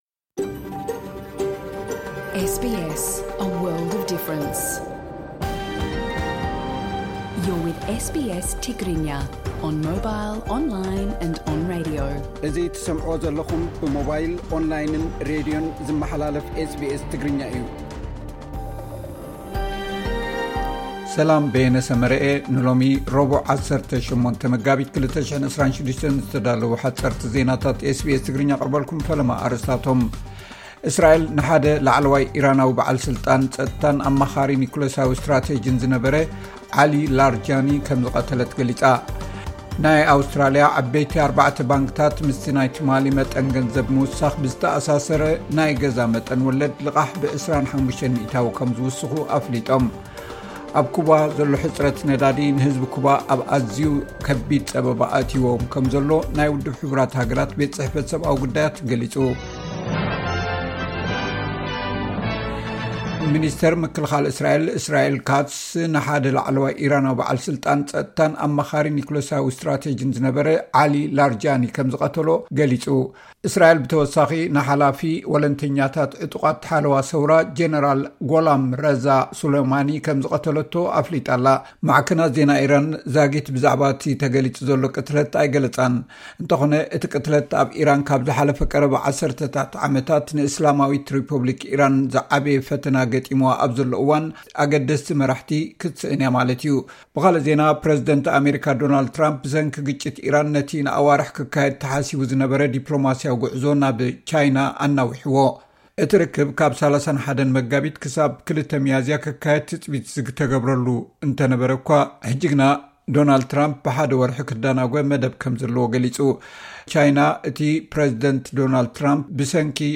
SBS Tigrinya Newsflash